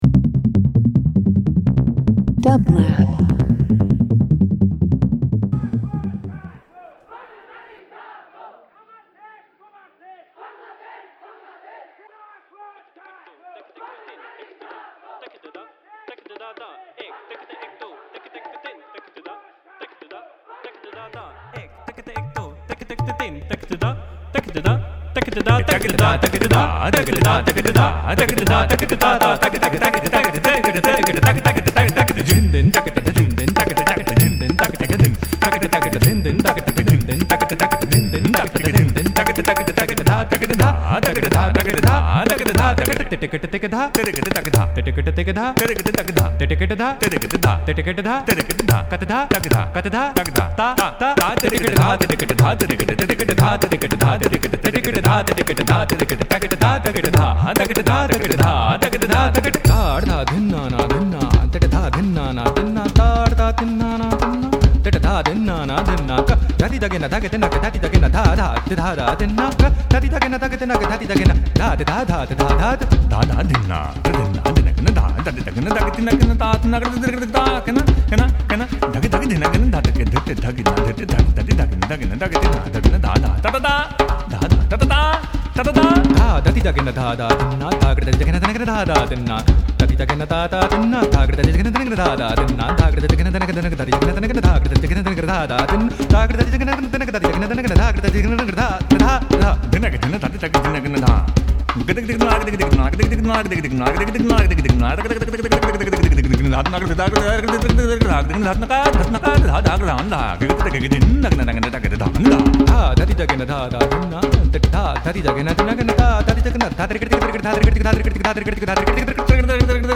Indian Traditional